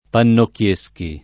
Pannocchieschi [ pannokk L%S ki ]